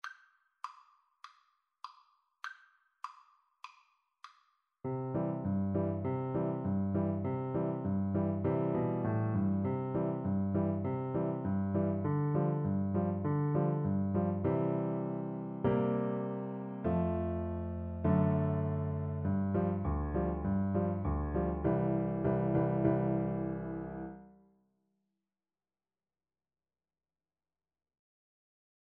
4/4 (View more 4/4 Music)
Piano Duet  (View more Beginners Piano Duet Music)